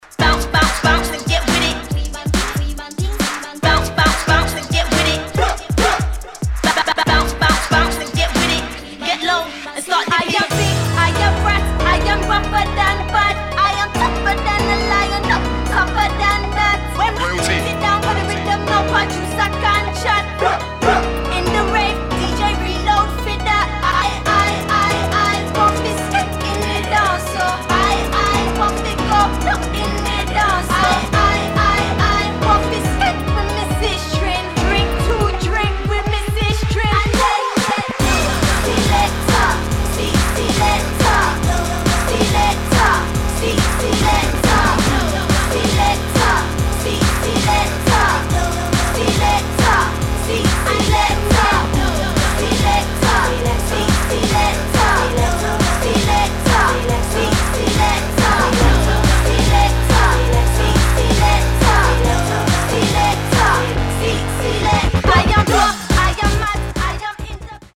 [ UK GARAGE / GRIME ]